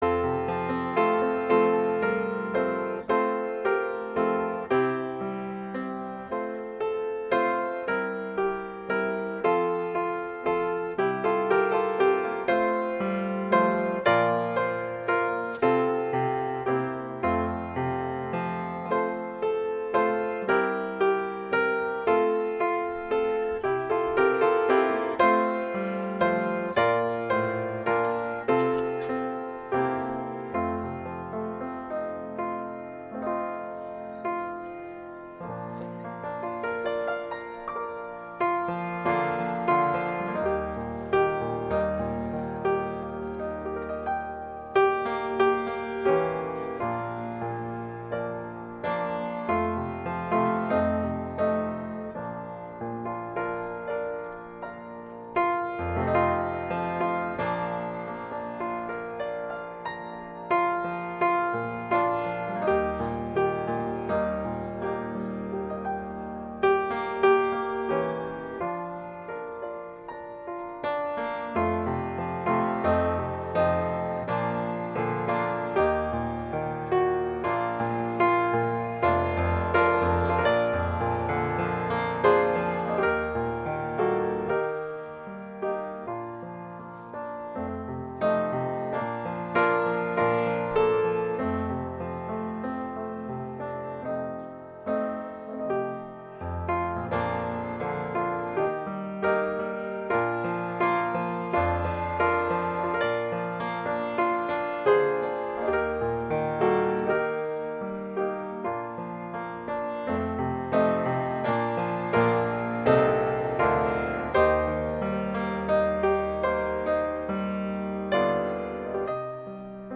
Weekly Sermons – Winchester Friends Church